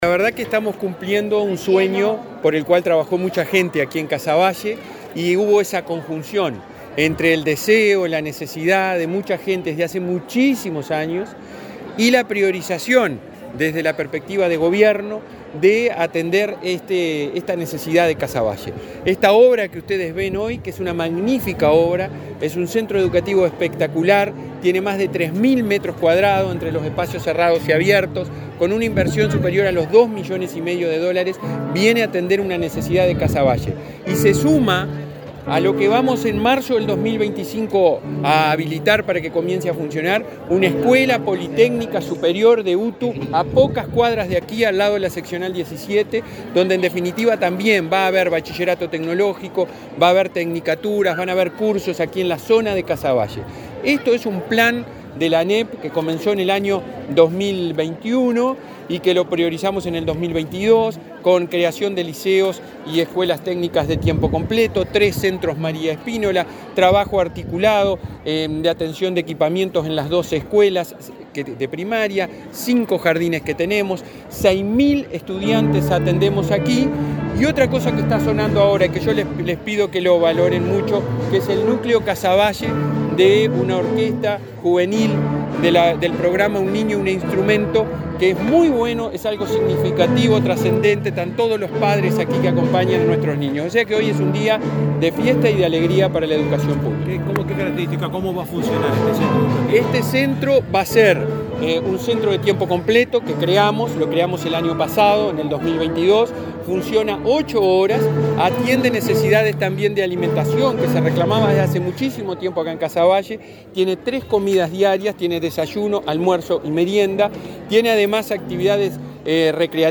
Declaraciones del presidente de la ANEP, Robert Silva
Declaraciones del presidente de la ANEP, Robert Silva 18/10/2023 Compartir Facebook X Copiar enlace WhatsApp LinkedIn El presidente de la Administración Nacional de Educación Pública (ANEP), Robert Silva, dialogó con la prensa antes de participar en la ceremonia de inauguración del edificio del liceo n.° 69, en el barrio Casavalle, en Montevideo.